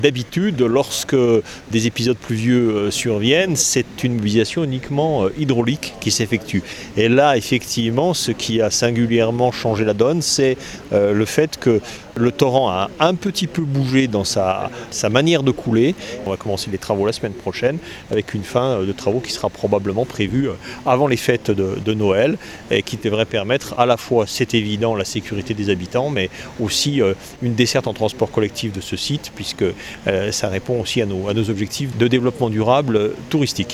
Eric Fournier, le maire de Chamonix.